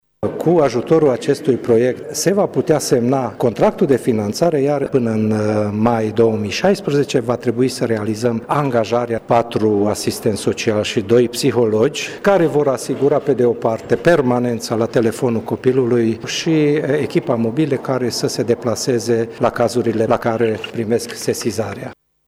Directorul DGASPC Mureș, Schmidt Lorand: